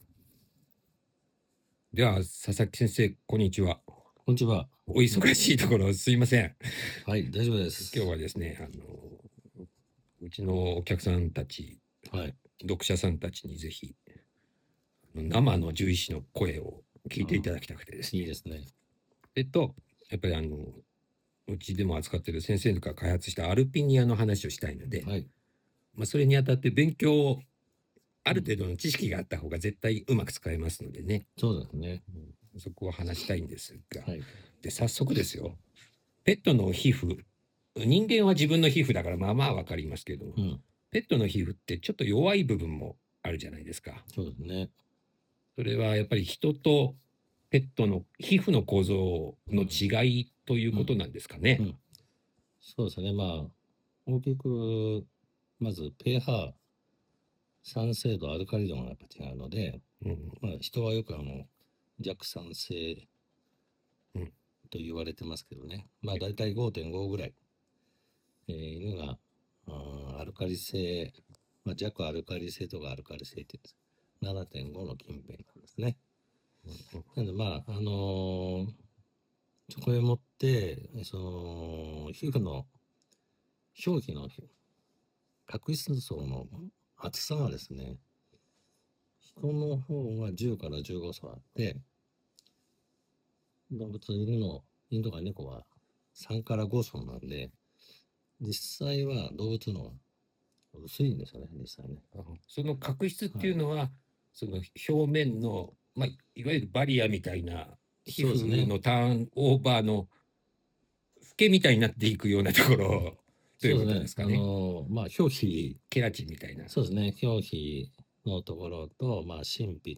和やかな雰囲気ですけど、会話の内容はけっこう鋭いです。